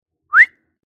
Whistling-Sound.wav